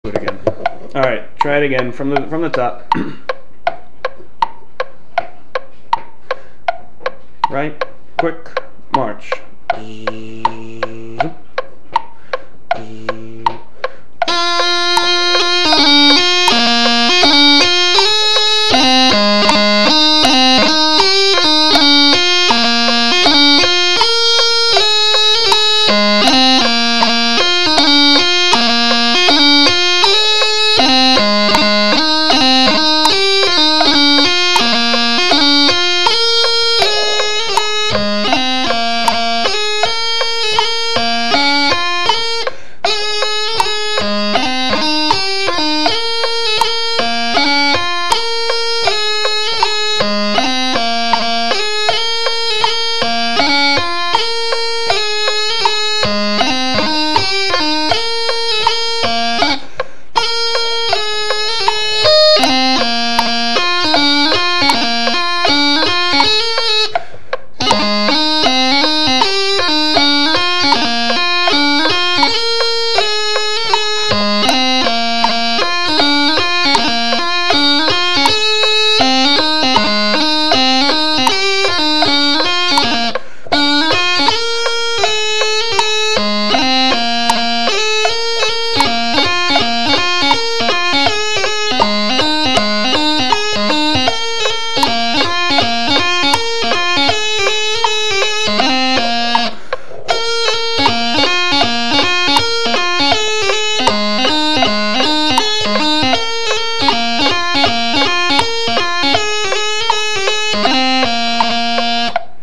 March: The Battle of Tamai
Battle of Tamai on practice chanter (mp3)